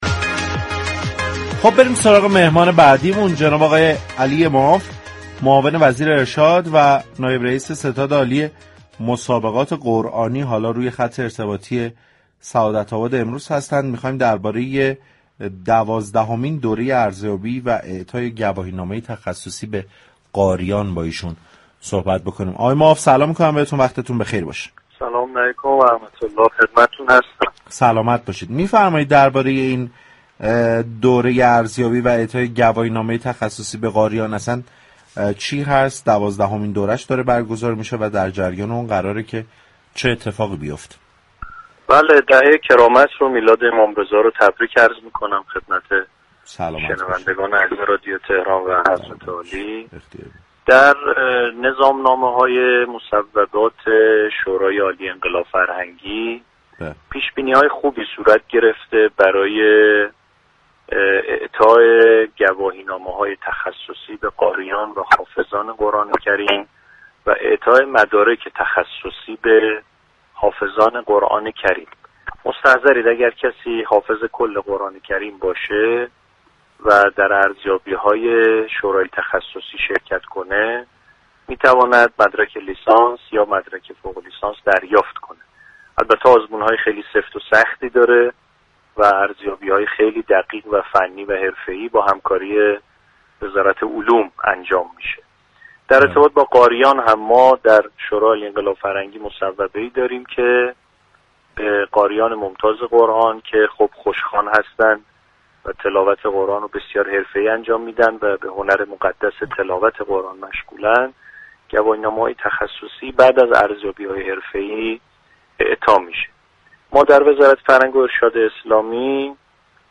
به گزارش پایگاه اطلاع رسانی رادیو تهران؛ علی معاف معاون وزیر فرهنگ و ارشاد اسلامی و نایب رئیس ستاد عالی مسابقات قرآنی در گفتگو با برنامه سعادت آباد 6 خرداد درباره برگزاری دوازدهمین دوره ارزیابی و اعطای گواهینامه تخصصی به قاریان ضمن تبریك دهه كرامت گفت: در نظامنامه های مصوبات شورای عالی انقلاب فرهنگی اعطای مدارك و گواهینامه تخصصی به قاریان و حافظان قرآن كریم پیش‌بینی ها خوبی انجام شده است.